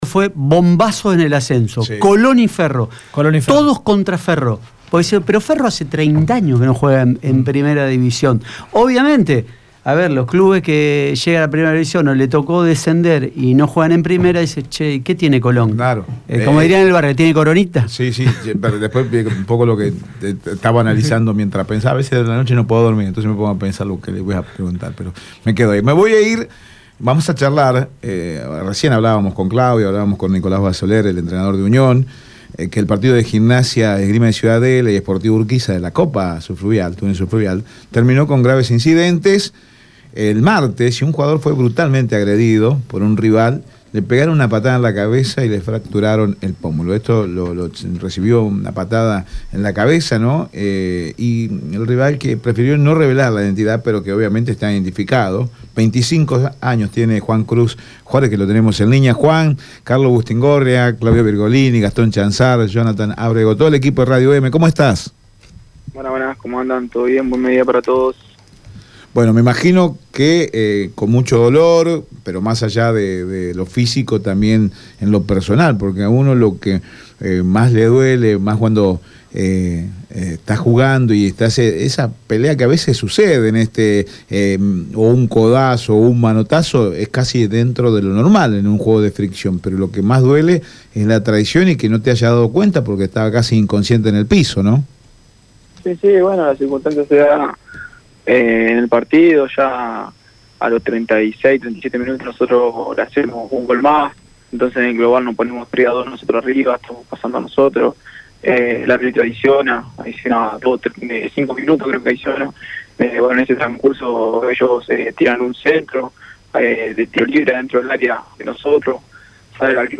En diálogo con EME Deportivo